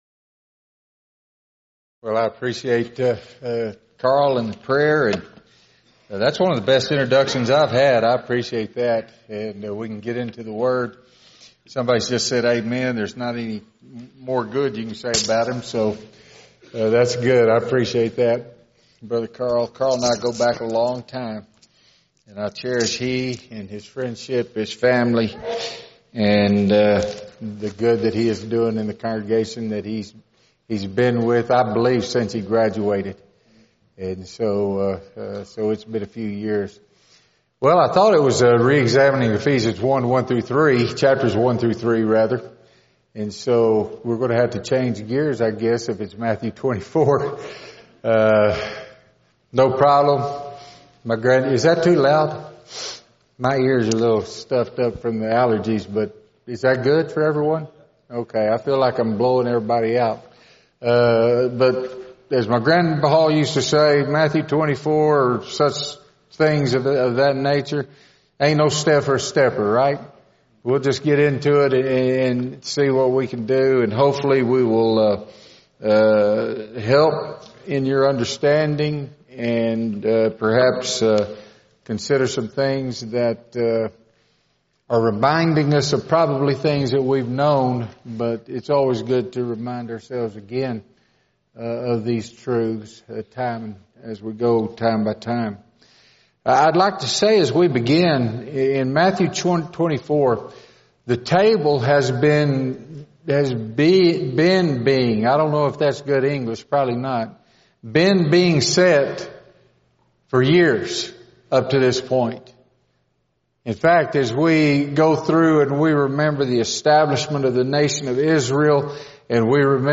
Event: 2018 Focal Point
lecture